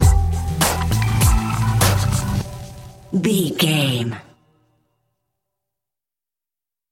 Aeolian/Minor
synthesiser
drum machine
hip hop
Funk
neo soul
acid jazz
energetic
cheerful
bouncy
funky
hard hitting